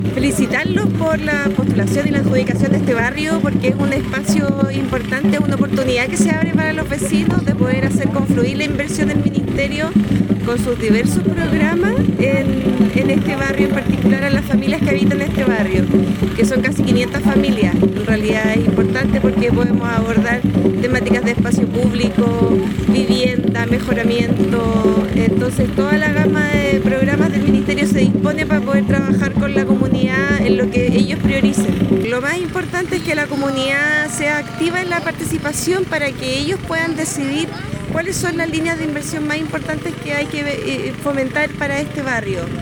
En el acto oficial que dio la bienvenida al Programa, se contó con masiva participación vecinal, quienes escucharon el discurso de autoridades locales y regionales.